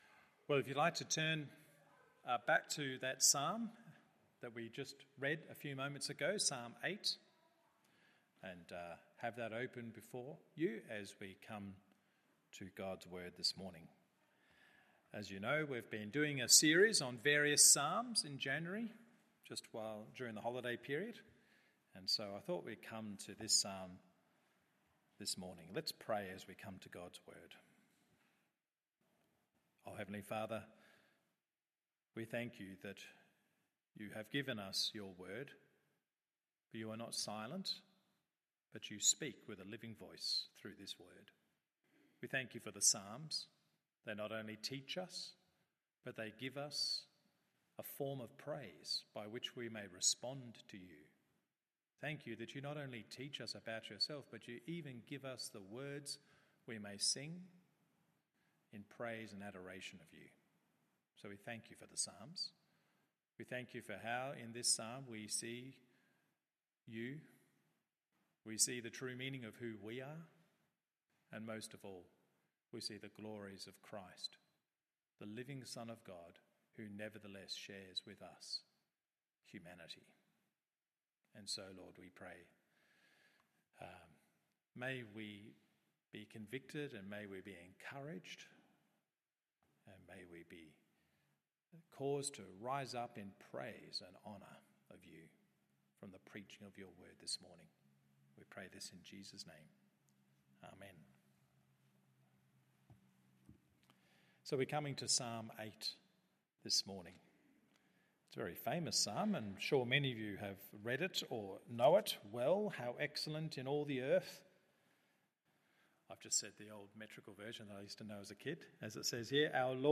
MORNING SERVICE Psalm 8…